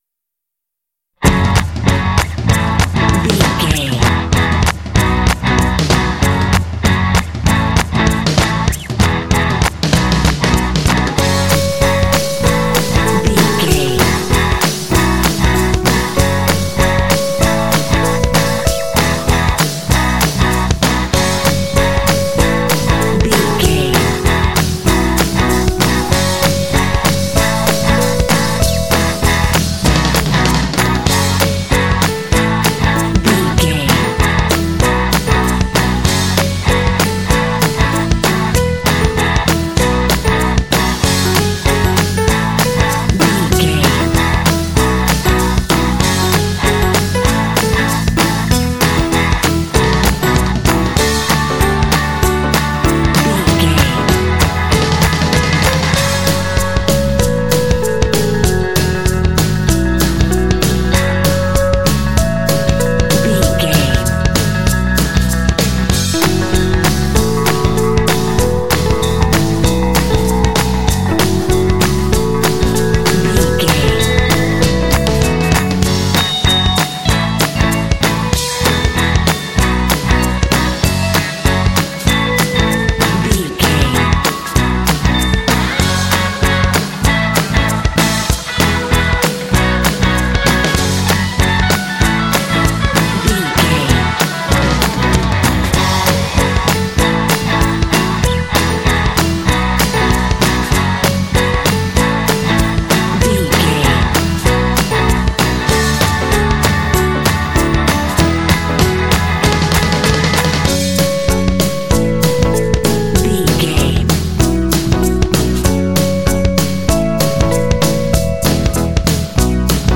Uplifting
Aeolian/Minor
F#
driving
energetic
lively
bass guitar
electric guitar
drums
percussion
electric piano
pop
rock
alternative rock
indie